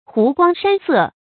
湖光山色 注音： ㄏㄨˊ ㄍㄨㄤ ㄕㄢ ㄙㄜˋ 讀音讀法： 意思解釋： 湖水風光；山巒秀色。形容山水景色之美。